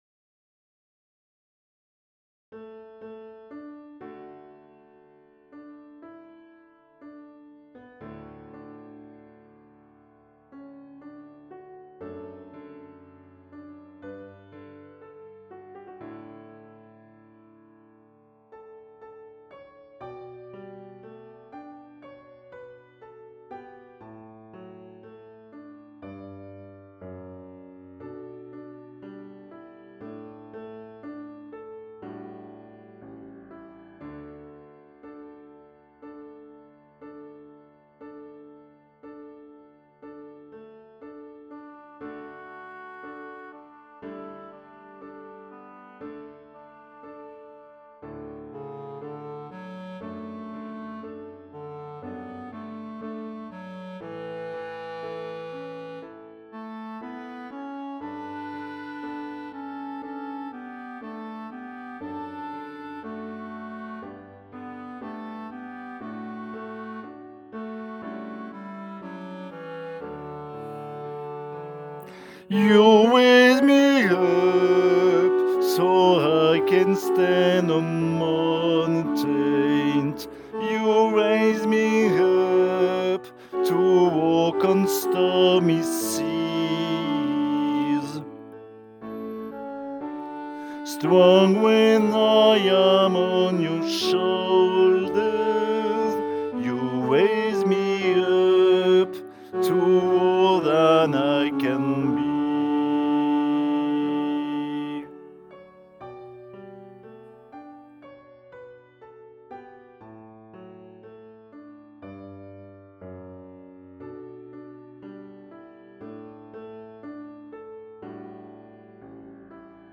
voix chantée